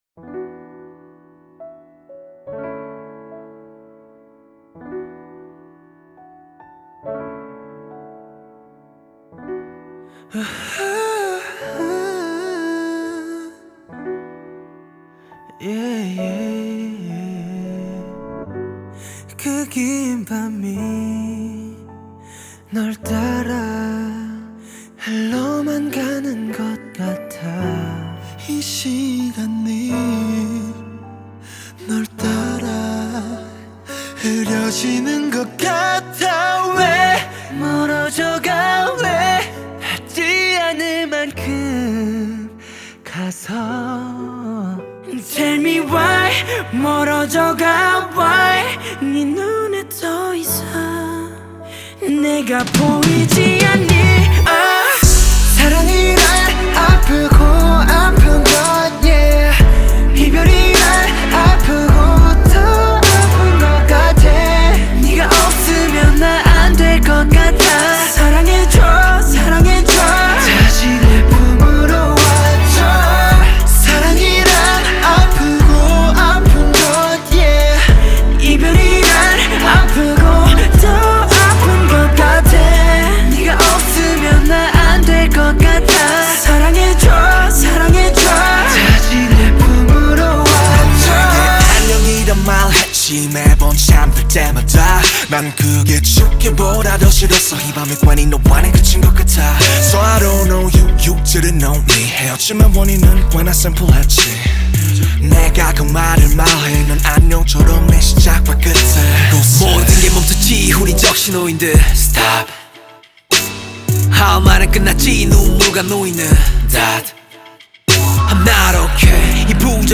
The South Korean boy group